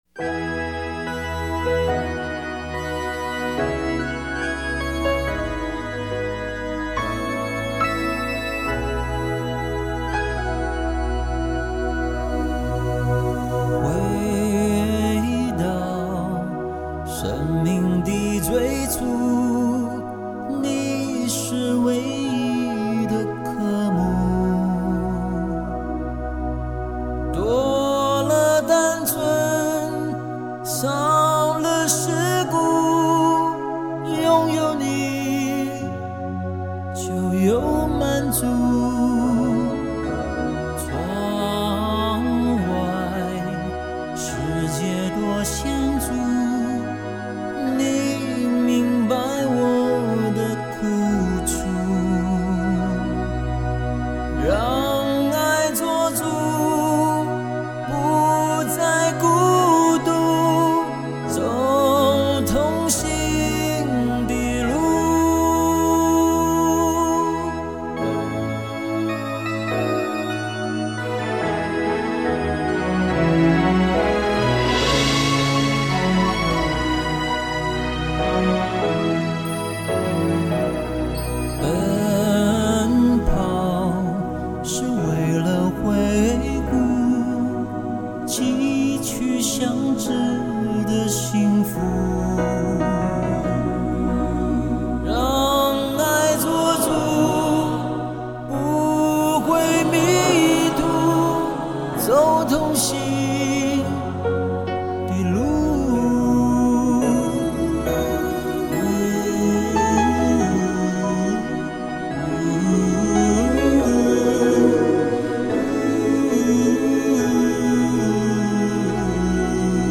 中国台湾流行乐男歌手
高密度34bit数码录音